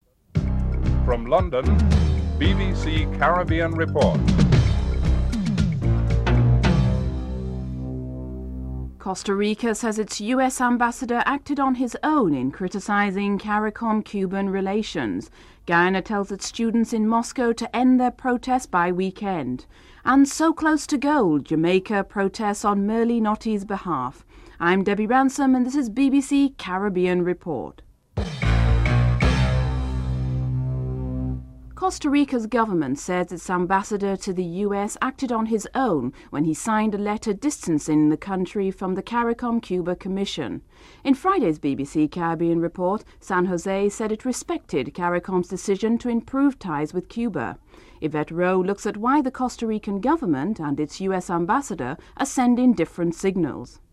1. Headlines (00:00-00:38)
Interview with Merlene Ottey, Olympic Champion winner (12:41-15:14)